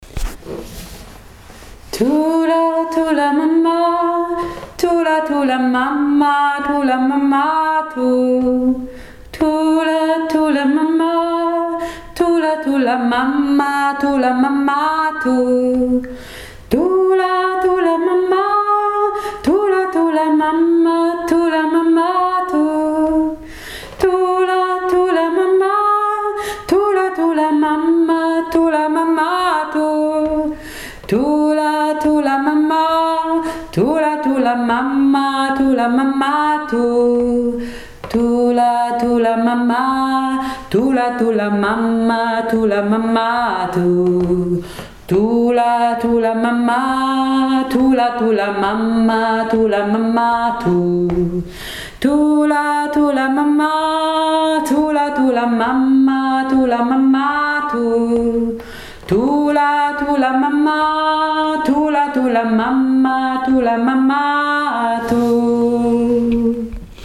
afrikanisches Lied